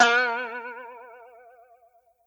Boing (3).wav